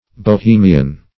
Bohemian \Bo*he"mi*an\, a.